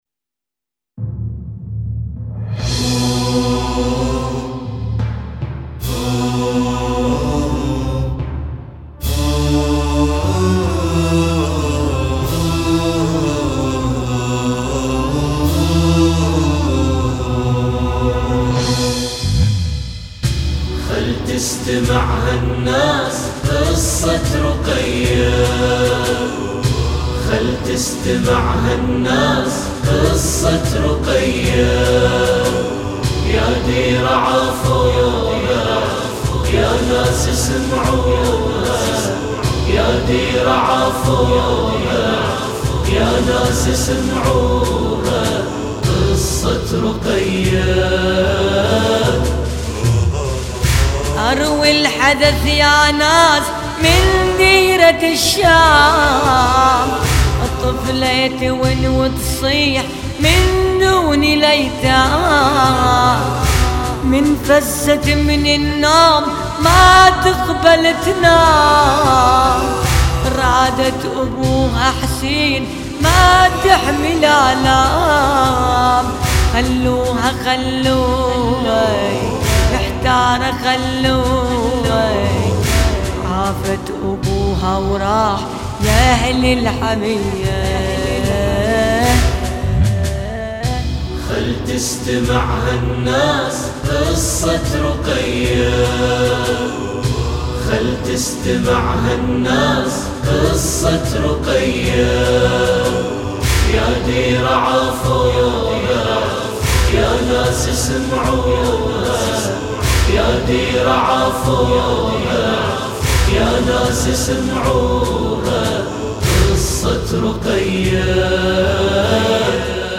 لطمية.. خل تسمع الناس قصة رقية